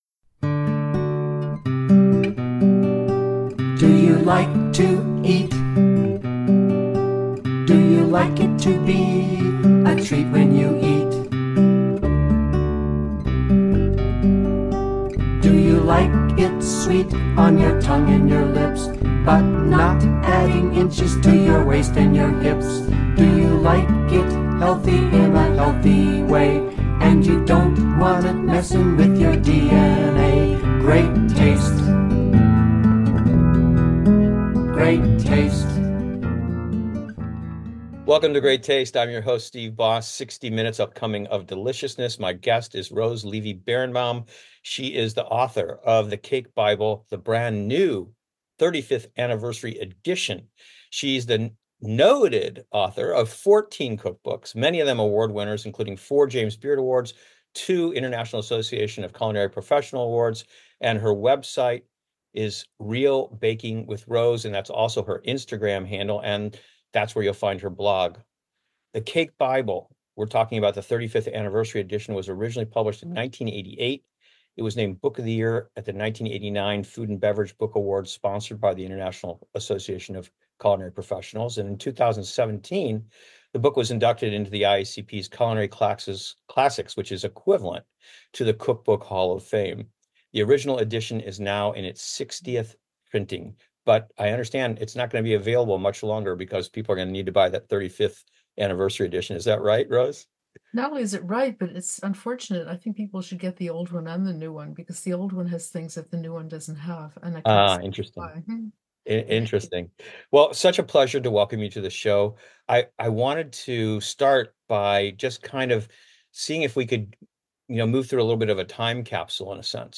Listen to past Great Taste episodes here. Great Taste is a weekly hour-long radio program, in its 19th year, currently airing on community radio station KICI 105.3 FM in Iowa City, Iowa.